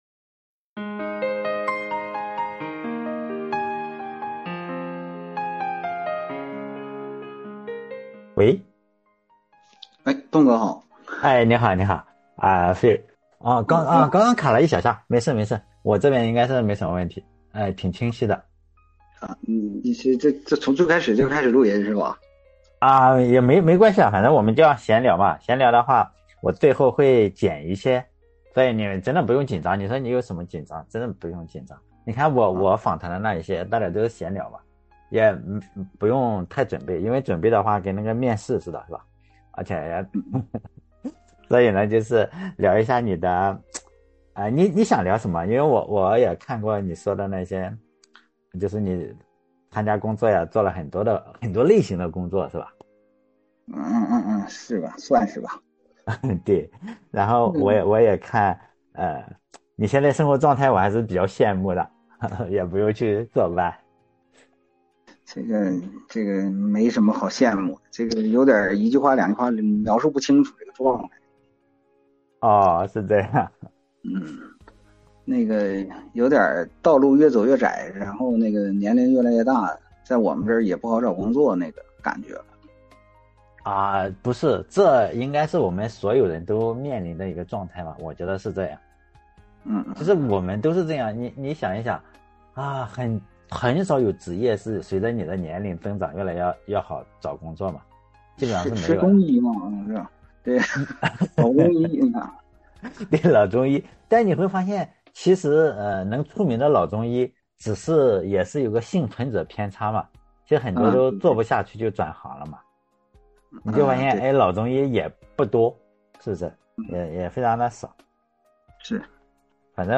No.481 两个奔五的人聊天，感慨人越来越老，路越走越窄了…….